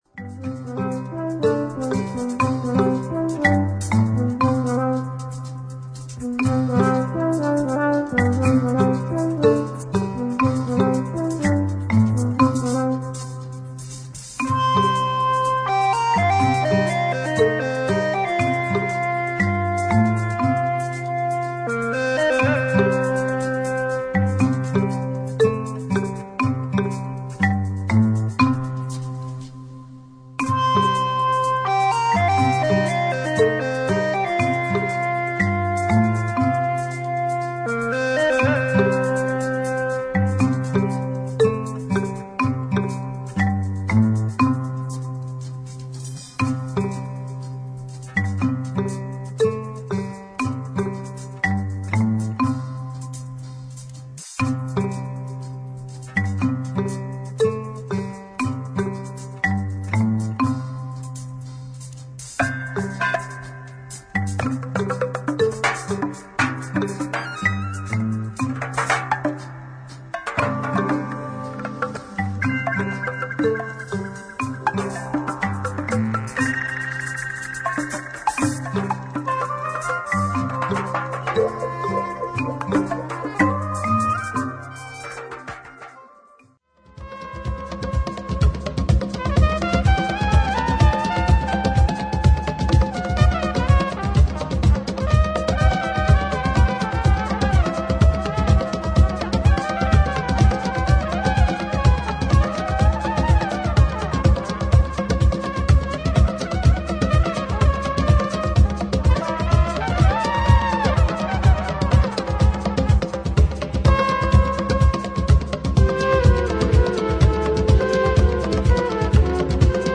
FORMAT : 12"